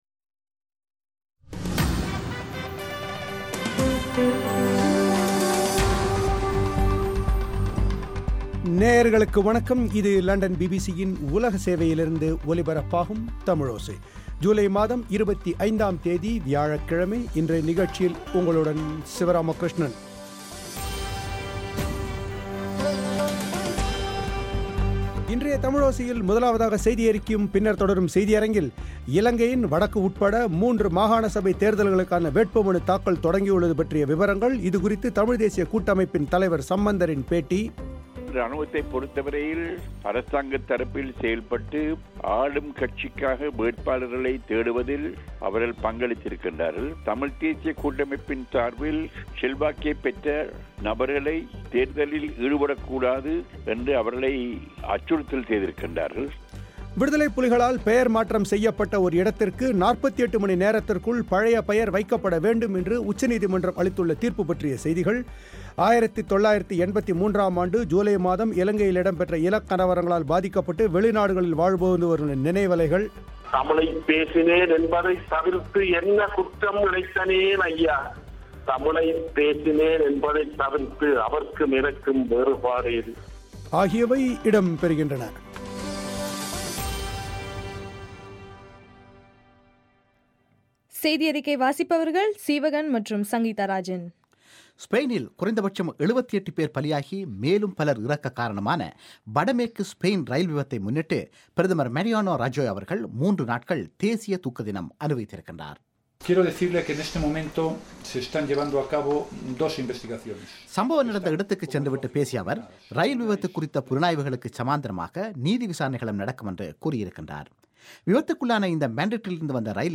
இது குறித்து தமிழ் தேசியக் கூட்டமைப்பின் தலைவர் சம்பந்தரின் பேட்டி